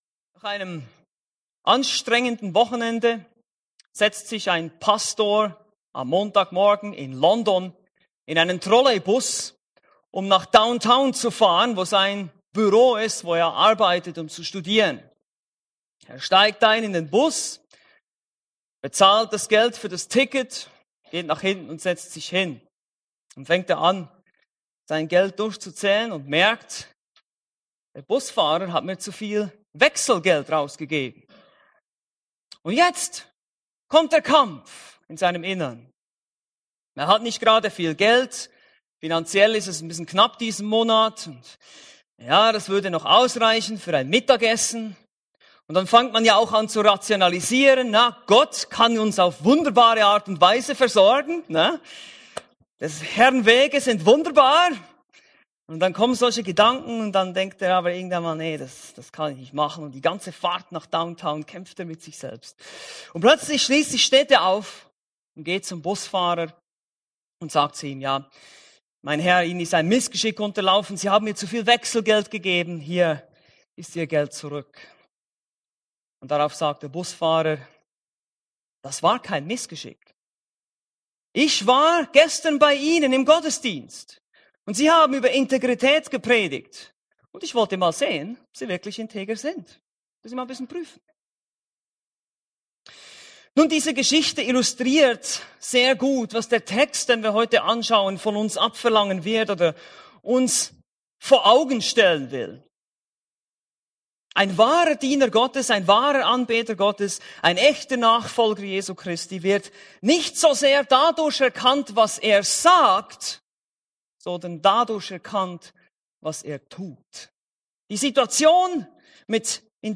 A predigt from the serie "Weitere Predigten."